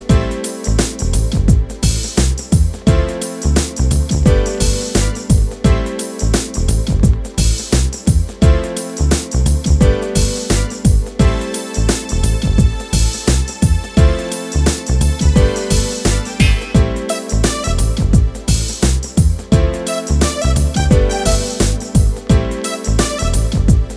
Duet Version Pop (1990s) 3:45 Buy £1.50